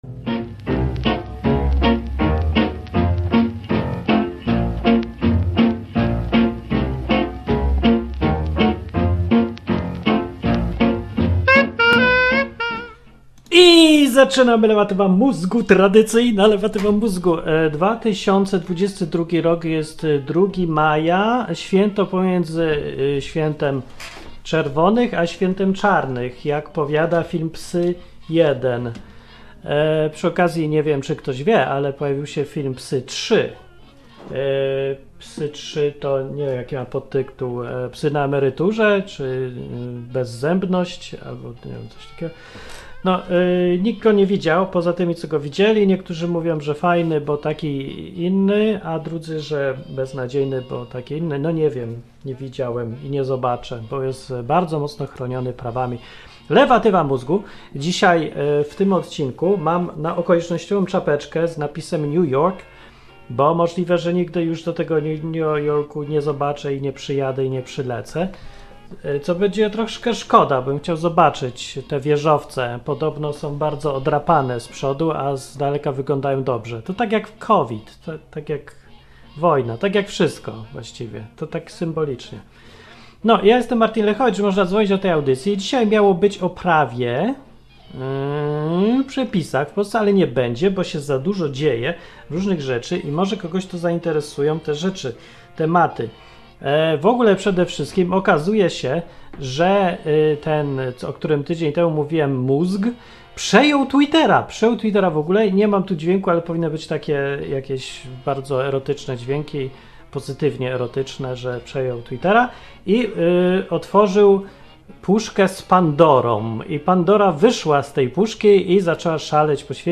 Chiny cenzurują same siebie. A do audycji dzwoni słuchacz niebinarny i opowiada o tym jak mu się żyje wśród binarnych.
Program satyryczny, rozrywkowy i edukacyjny.